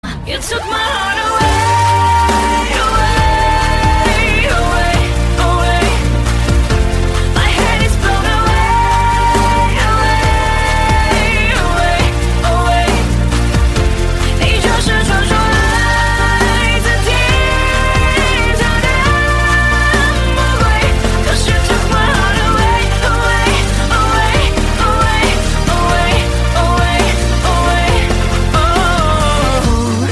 Thể loại nhạc chuông: Nhạc trung hoa